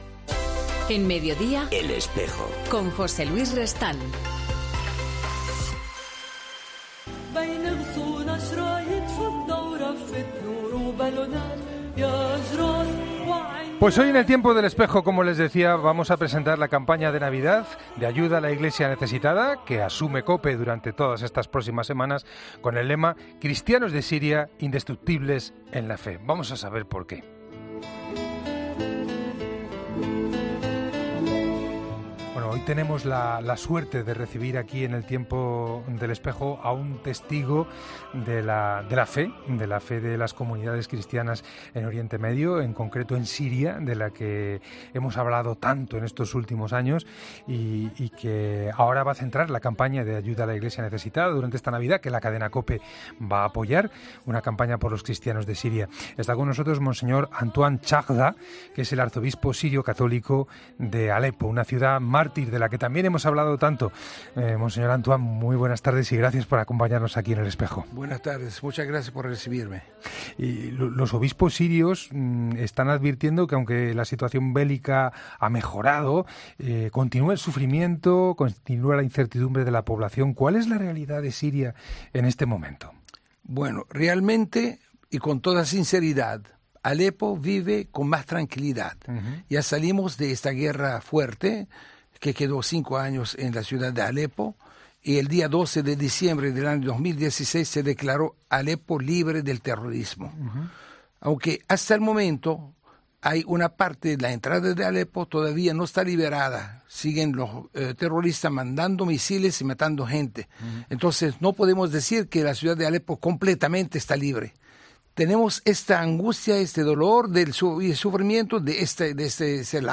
Campaña de Navidad de Ayuda a la Iglesia Necesitada con Mons. Antoine Chahda, Arzobispo de Alepo